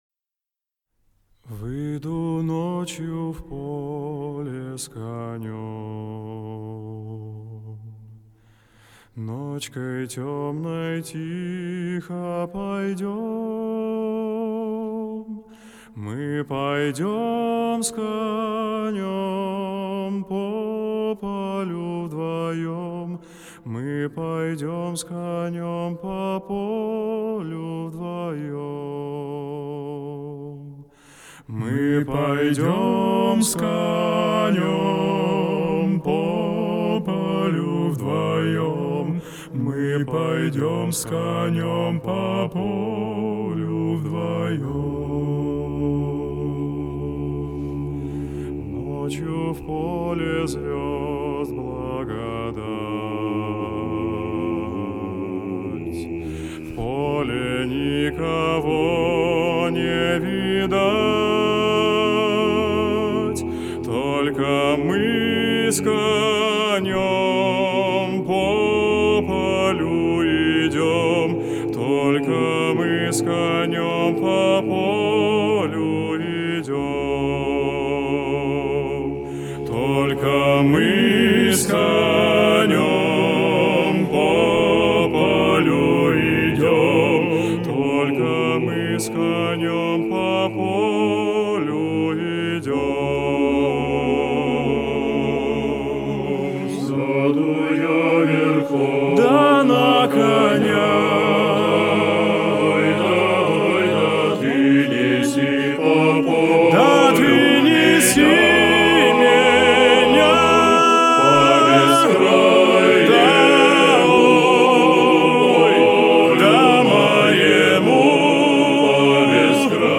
русские народные песни
застольные песни Размер файла